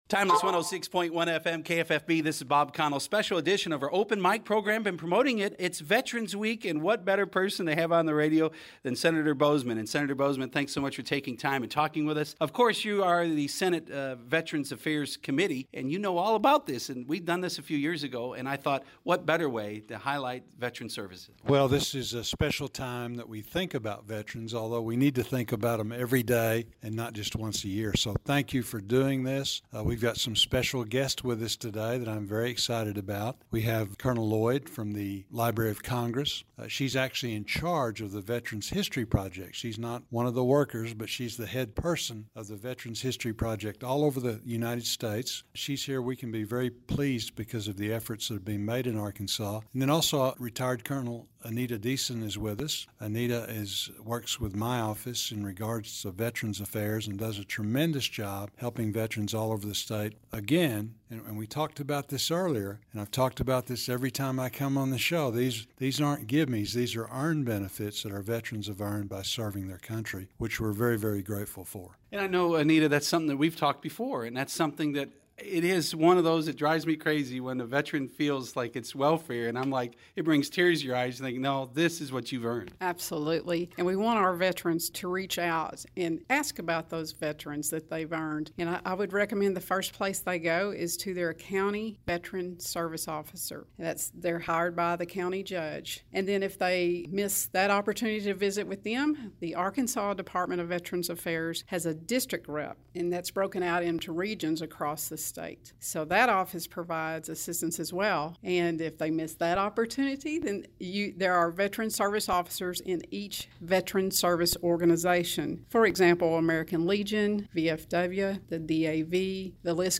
U.S. Senator John Boozman (R-AR) on KFFB’s Open Mic Day #1 for Veterans Week, November 12th-16th.
KFFBs-Open-Mic-with-Senator-John-Boozman.mp3